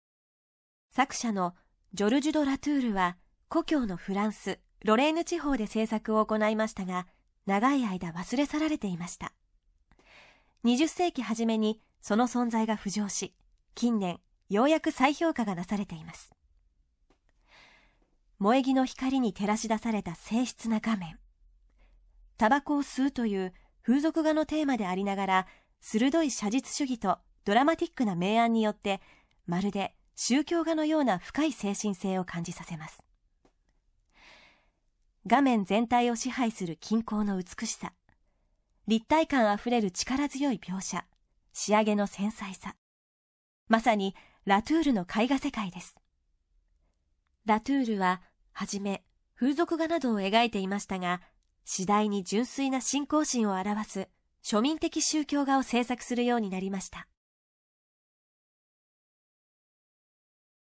作品詳細の音声ガイドは、すべて東京富士美術館の公式ナビゲーターである、本名陽子さんに勤めていただいております。本名さんは声優、女優、歌手として幅広く活躍されています。